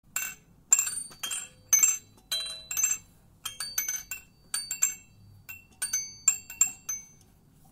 На этой странице вы найдете звуки неваляшки — знакомые с детства мелодичные переливы и покачивания.
Пластиковая игрушка-неваляшка roly-poly